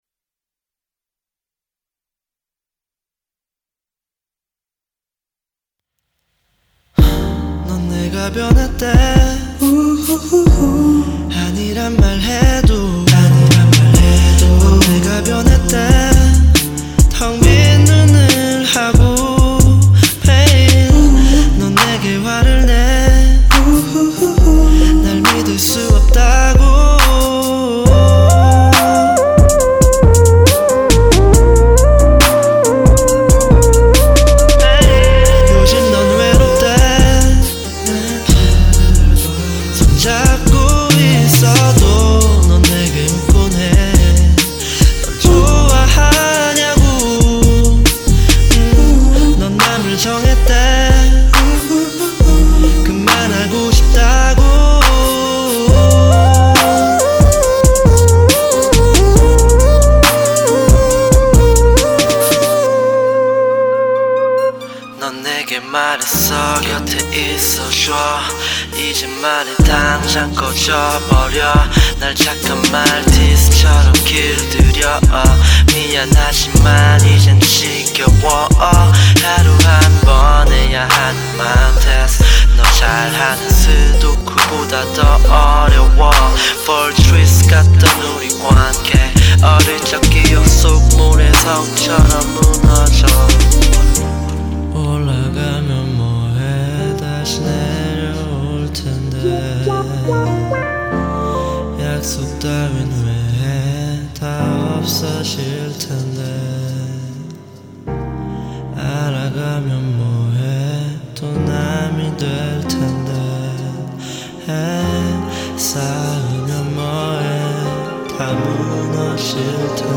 synthesizer
vocal / rap